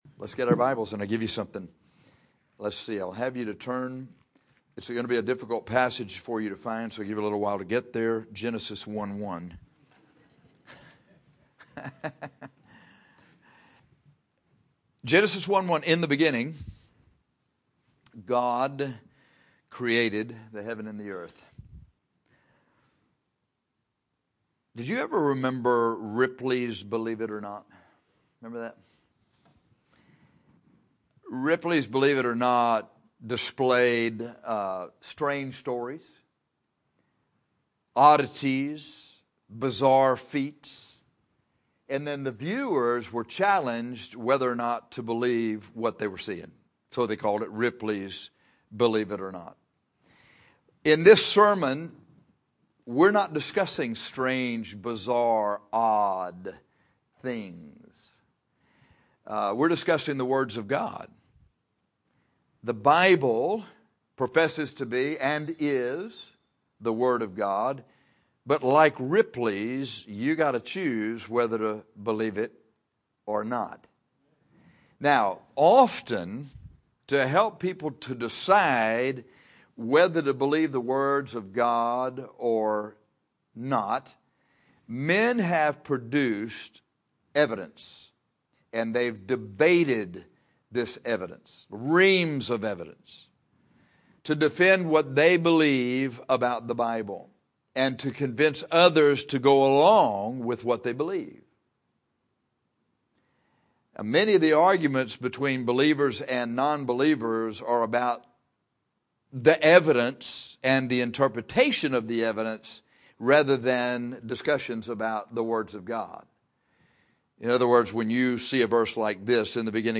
But in this sermon we’re not discussing strange, bizarre, and odd things.